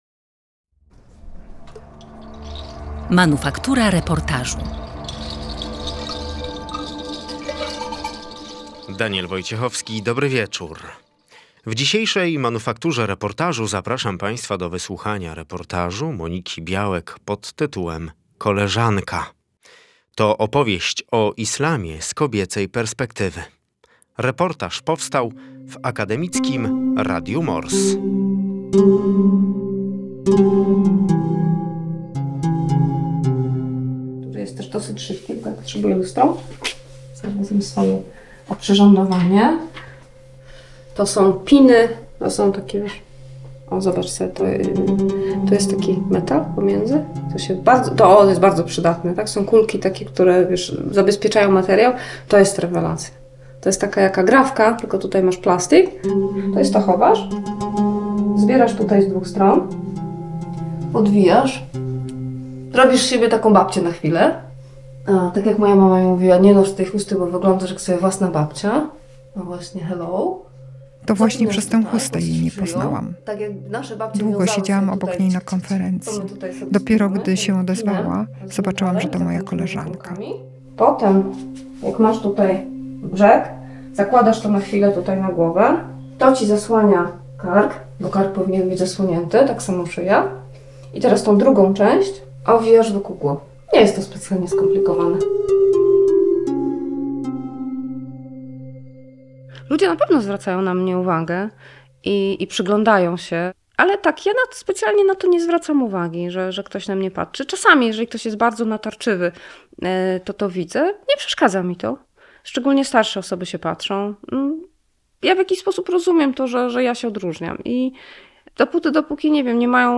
Reportaż „Koleżanka”, czyli islam z perspektywy kobiecej